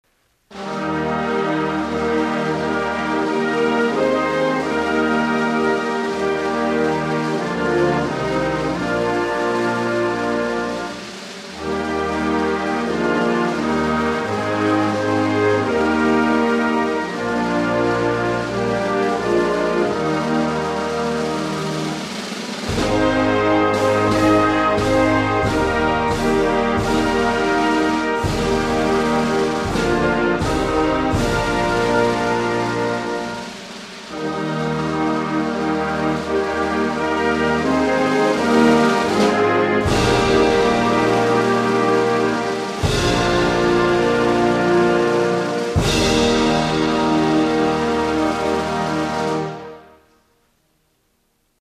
Die Hymne selbst ist traditionell geprägt. Ihr feierlicher, hymnenartiger Charakter erinnert an fürstliche Zeiten.